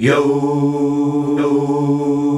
YOOOOH  D.wav